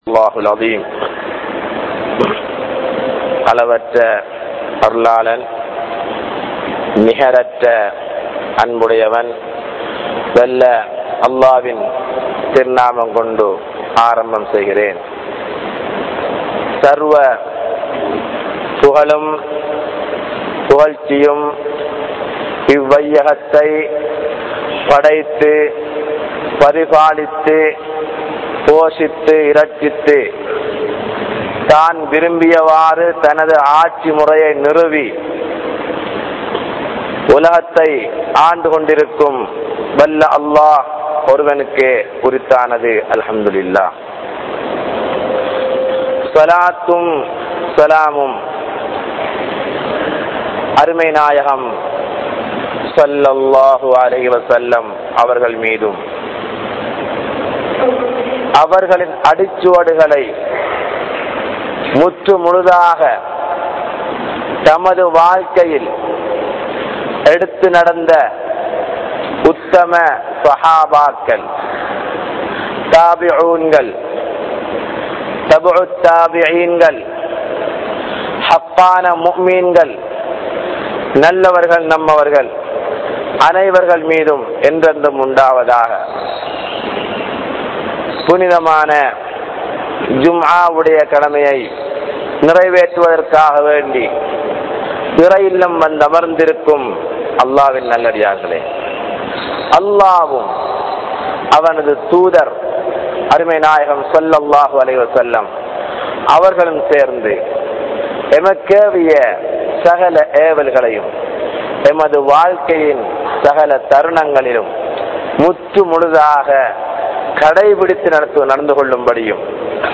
Indraya Muslimkalidathil Islam Ullathaa? | Audio Bayans | All Ceylon Muslim Youth Community | Addalaichenai
Grand Jumua Masjitth